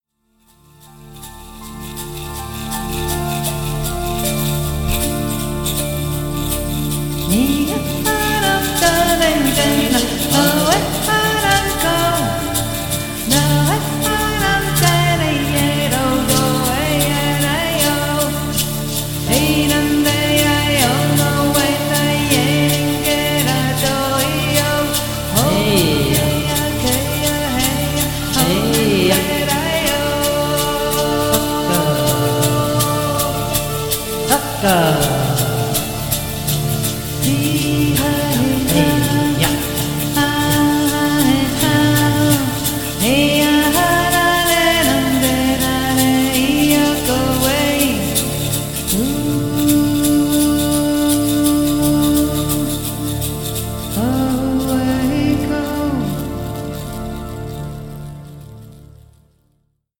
Erdklangflöte
intuitive Musik
Seelenmusik